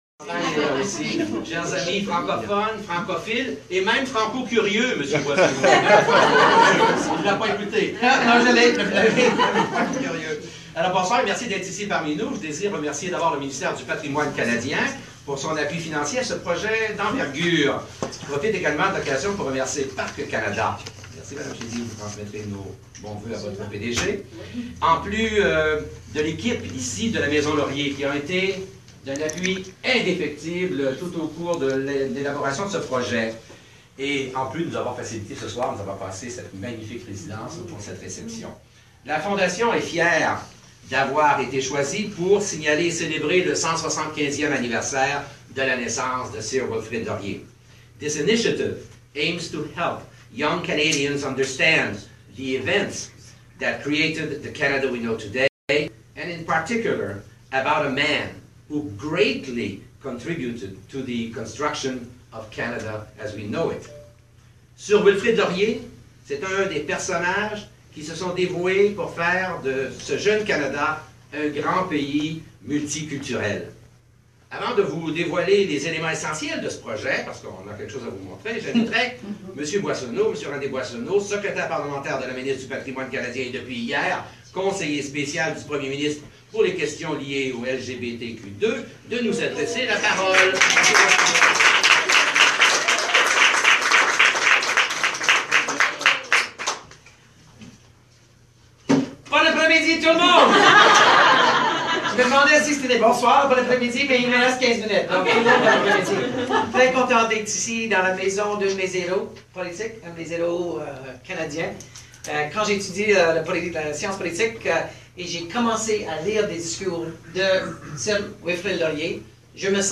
Launch event Speeches
Laurier_Video_audio_launch_speeches.mp3